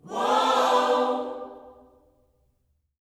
WHOA-OHS 6.wav